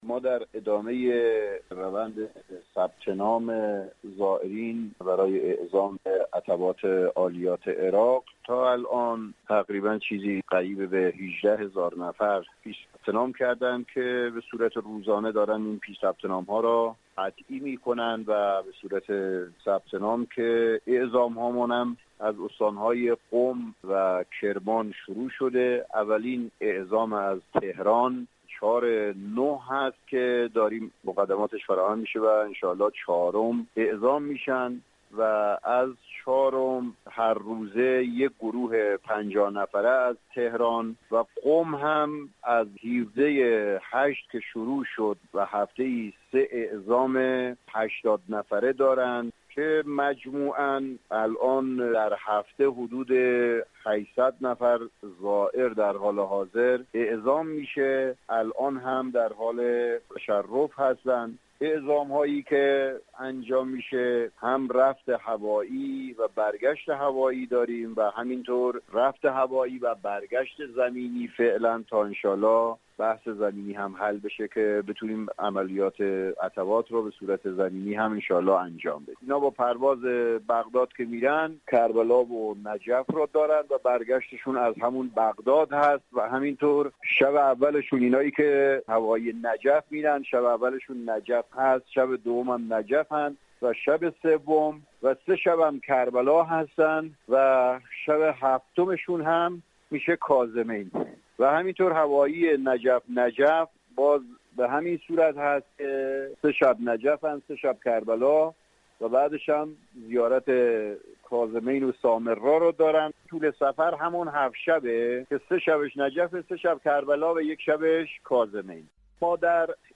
بهگزارش رادیو زیارت ، معاون عتبات عالیات سازمان حج و زیارت در برنامه گفتگوی خبری رادیو با تاکید بر اینکه اعزام ها در حال حاضر فقط به صورت هوایی است، اظهار داشت : در ادامه ثبت نام از زائران برای اعزام به عتبات عالیات تاکنون حدود 18 هزار نفر پیش ثبت نام کرده اند.